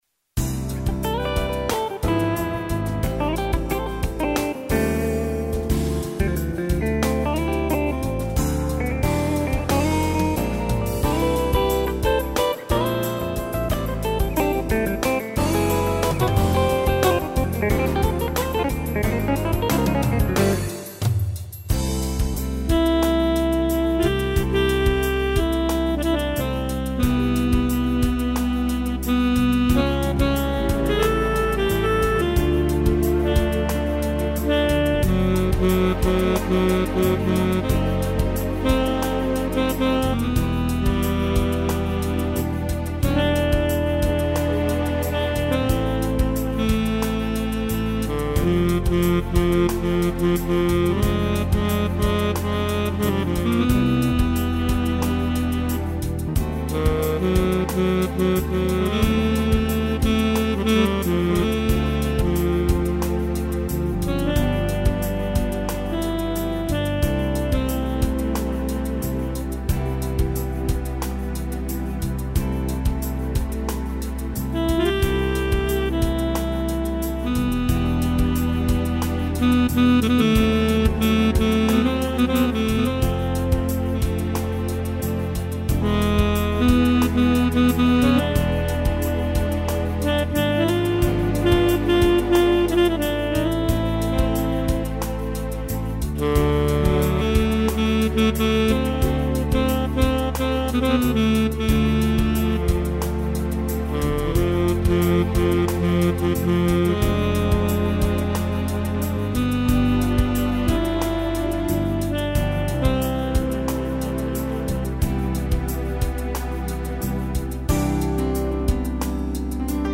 (solo sax)